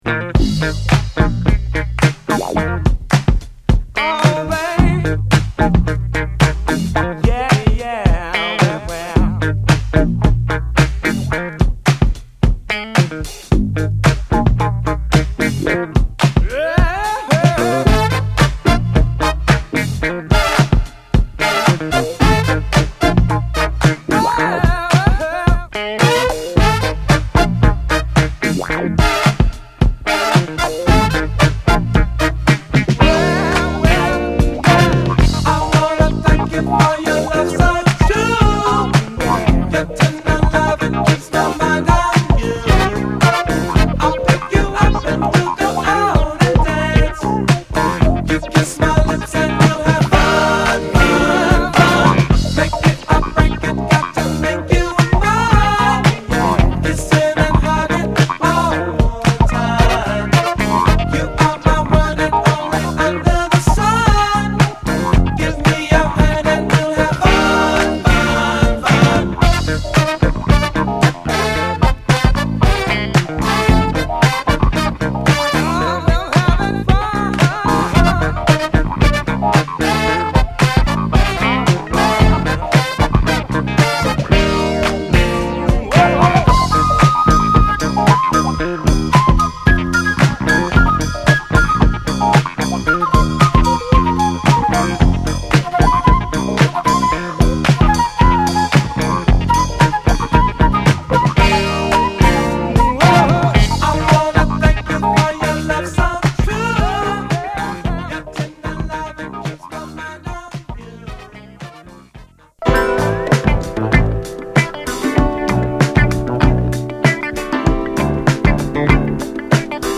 アルバム通してグッドメロウ〜グッドファンクを満載！